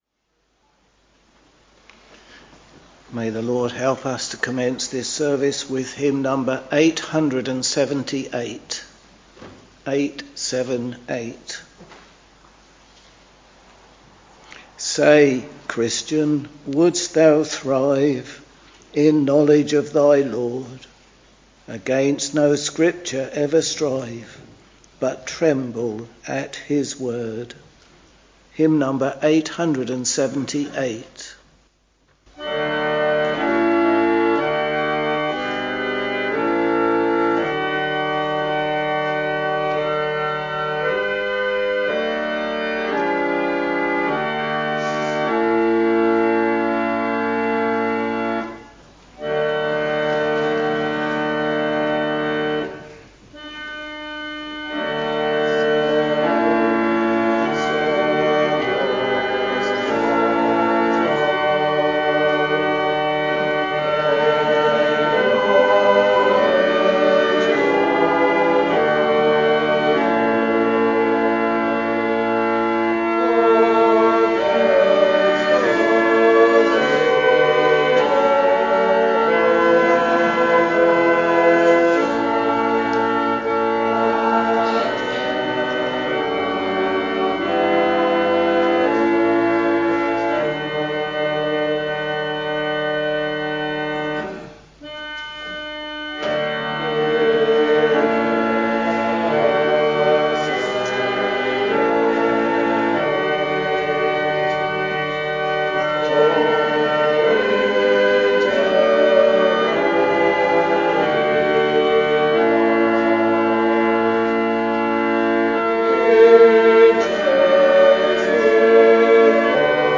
Week Evening Service Preacher
Hymns: 878, 14, 264 Reading: Psalm 119:49-64; Psalm 119:81-96 Bible and hymn book details Listen Download File